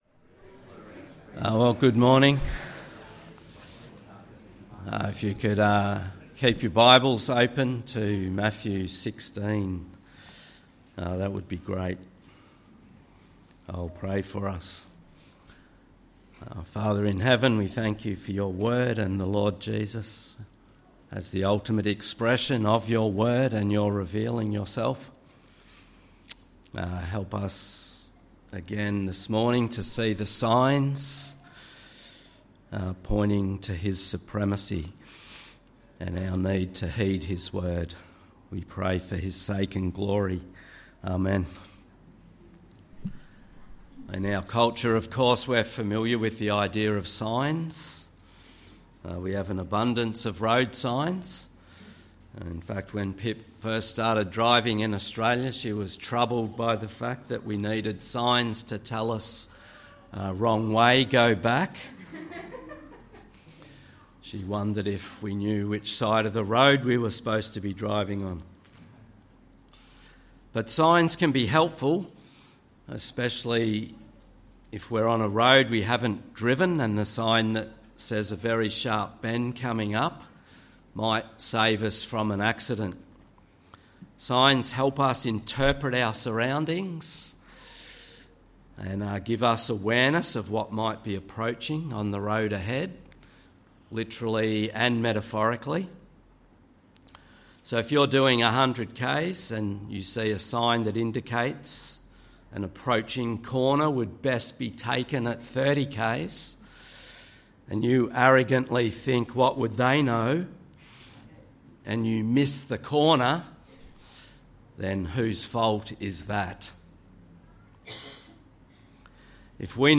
Sermon: Matthew 16:1-12